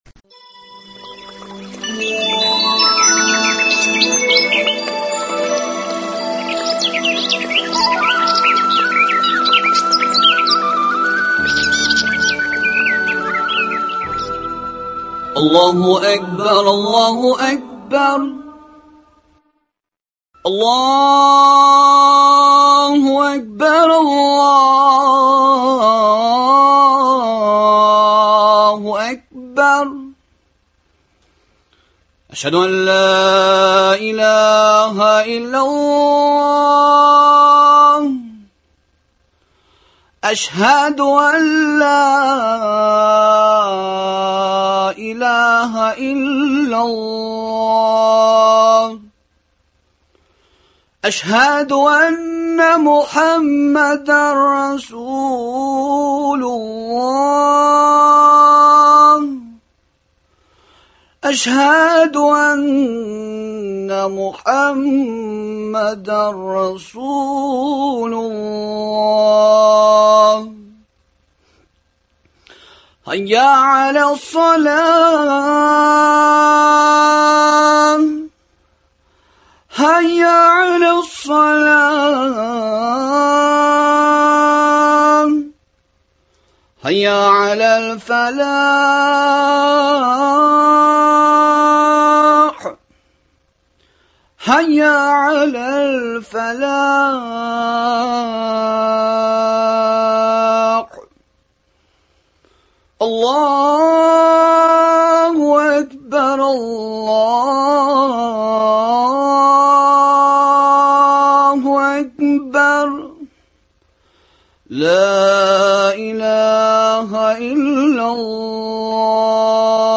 дога кылу тәртипләрен өйрәтү максатында оештырылган әңгәмәләр циклы.